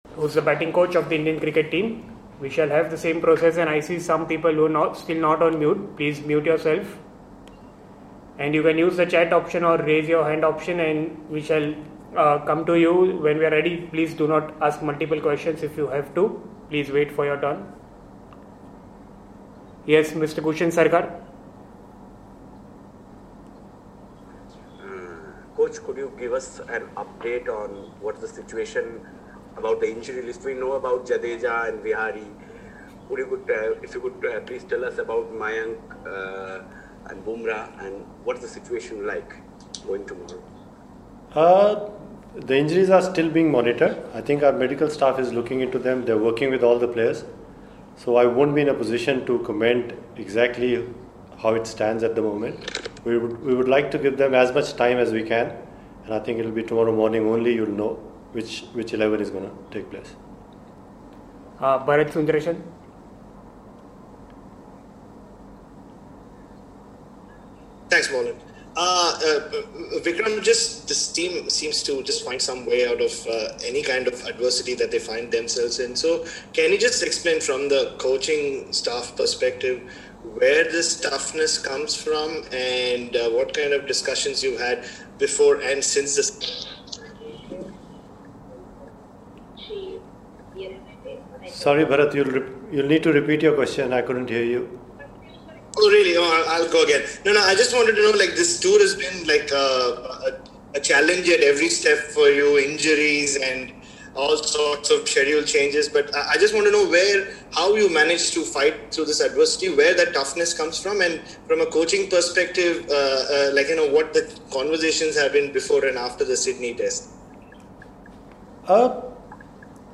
virtual press conference